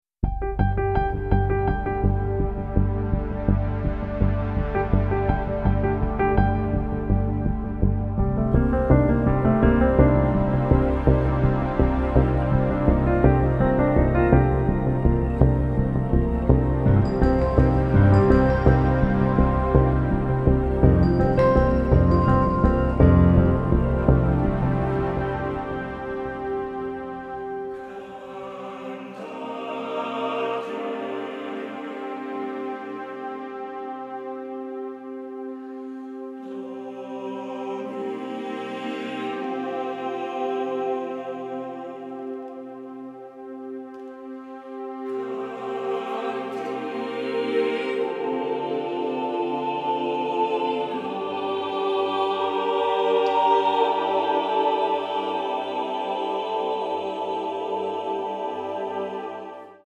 orchestra and chorus